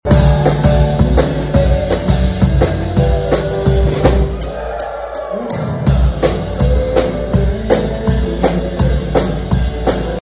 Dueling Pianos begins performing at the Emporia Main Street Public Improvement Auction on Saturday.
The atmosphere was lively from start to finish as Emporia Main Street held its Public Improvement Auction on Saturday at the Granada Theatre.
A big part of the atmosphere was the return of Dueling Pianos.
0256-dueling.mp3